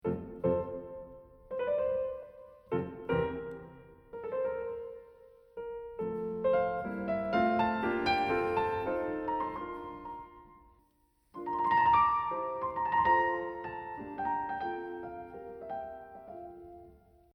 But whoa! That does not feel like an end to anything at all, does it?
Listen again to the beautiful moment at 0.09-0.10 when the music keeps “flying” instead of “landing”: